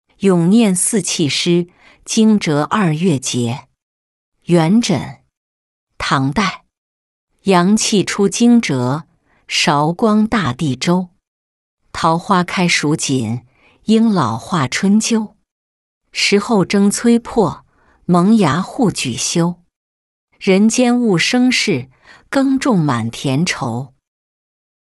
咏廿四气诗·惊蛰二月节-音频朗读